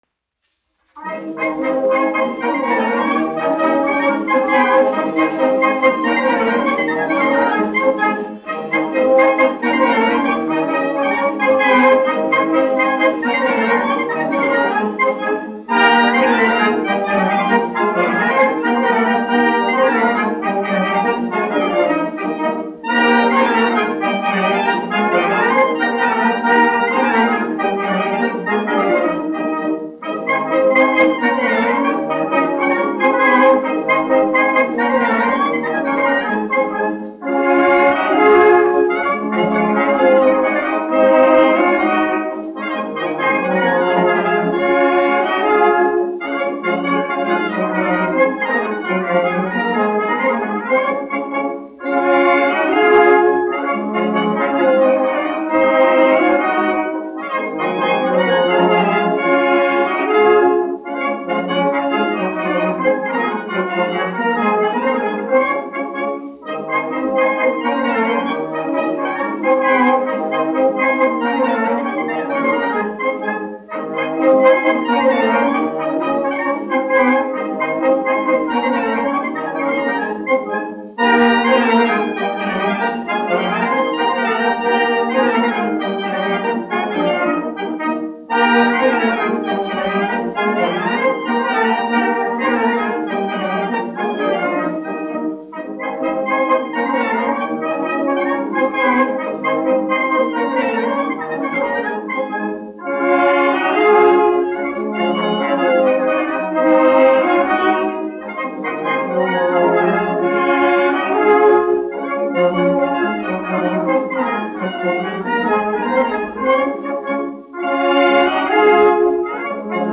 1 skpl. : analogs, 78 apgr/min, mono ; 25 cm
Polkas
Pūtēju orķestra mūzika
Latvijas vēsturiskie šellaka skaņuplašu ieraksti (Kolekcija)